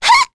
Miruru-Vox_Attack1.wav